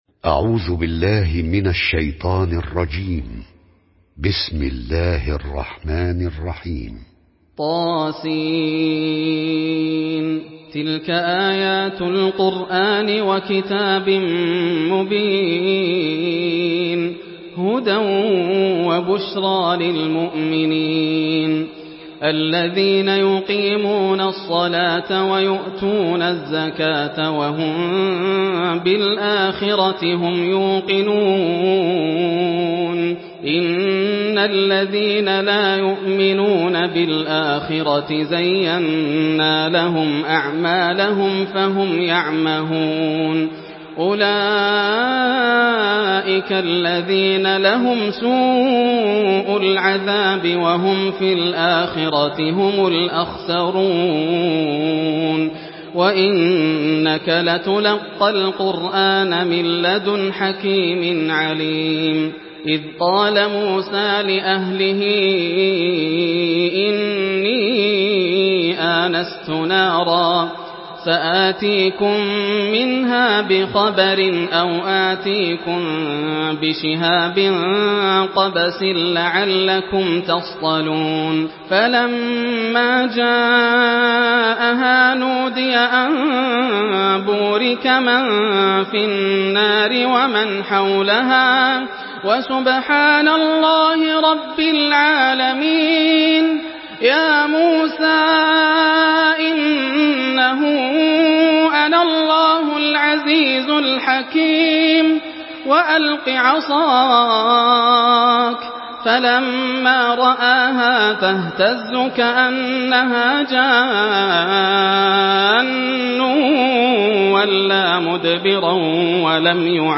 Surah Neml MP3 in the Voice of Yasser Al Dosari in Hafs Narration
Murattal Hafs An Asim